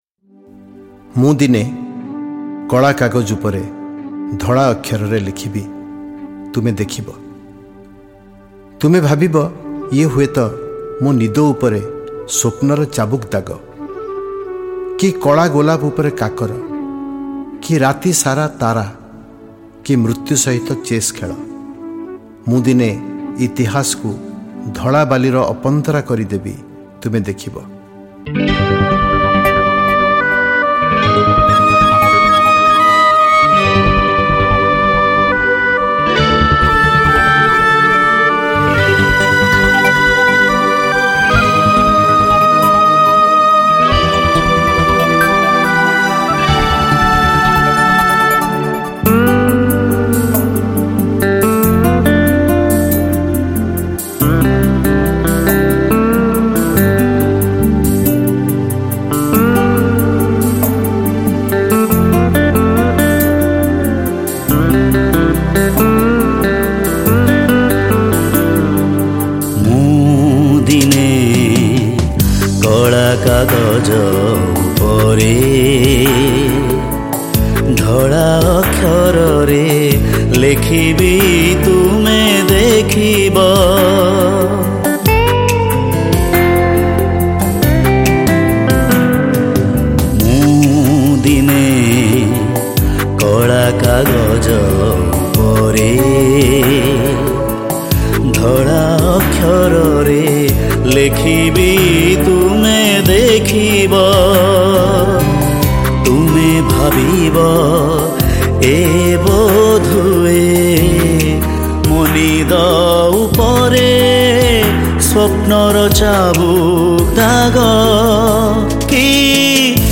Category: Blue Mood